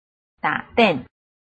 臺灣客語拼音學習網-客語聽讀拼-詔安腔-鼻尾韻
拼音查詢：【詔安腔】den ~請點選不同聲調拼音聽聽看!(例字漢字部分屬參考性質)